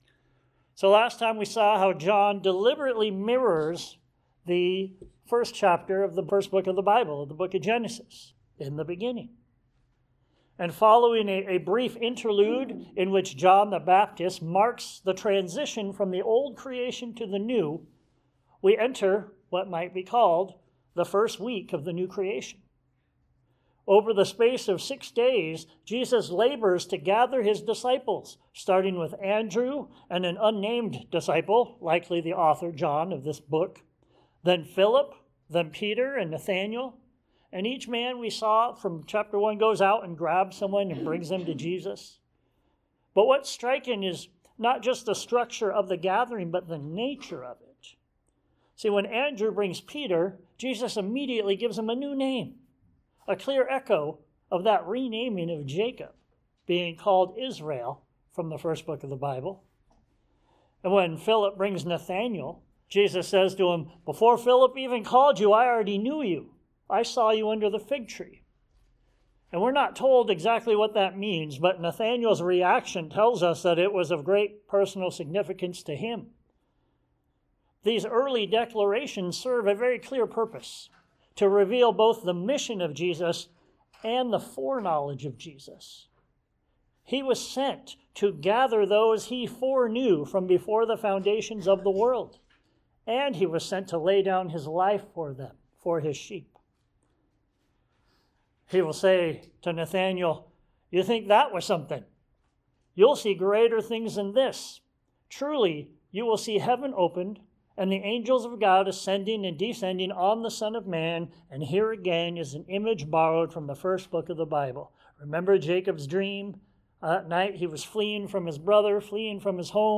A Word on Wine & Whips John 2 Sermons Share this: Share on X (Opens in new window) X Share on Facebook (Opens in new window) Facebook Like Loading...